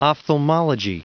Prononciation du mot ophthalmology en anglais (fichier audio)
Prononciation du mot : ophthalmology